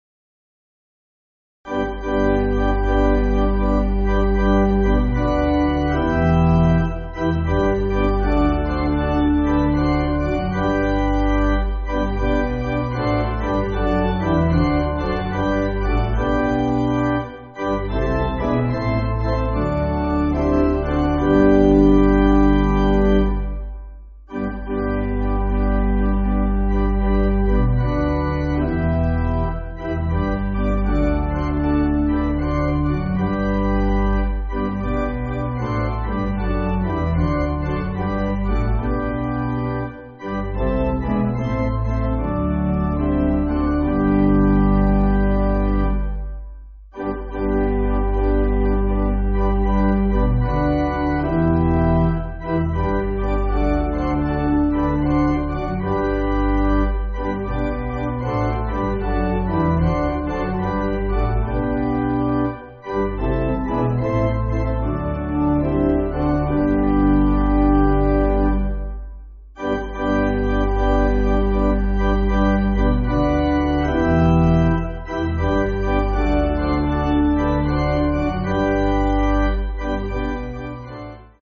Organ
(CM)   5/G